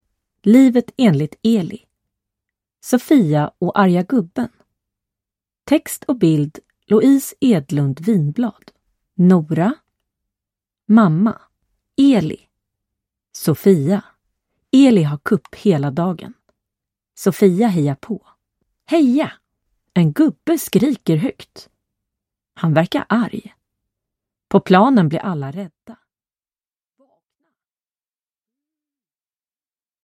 Sofia och arga gubben (ljudbok